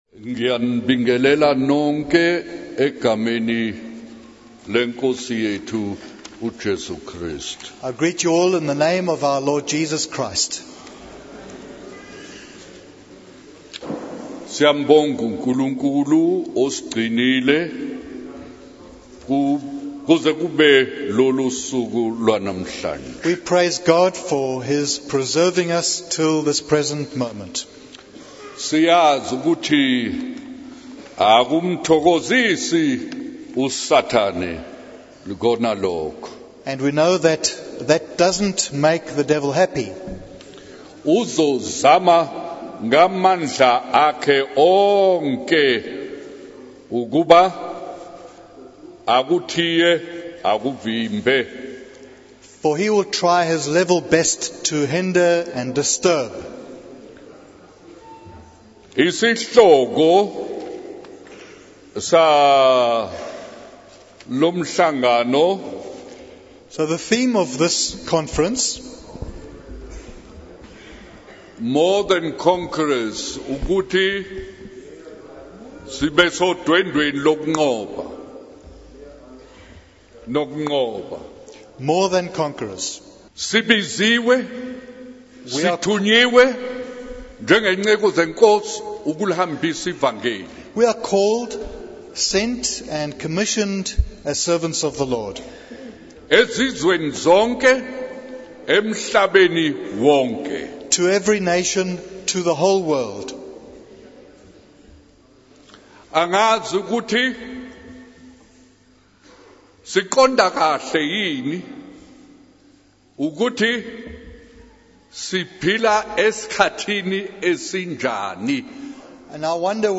In this sermon, the preacher emphasizes the importance of conquering in the name of the Lord in order to overcome the difficulties of the world. The speaker encourages the audience to confess all their known sins and deal with anything doubtful in their lives.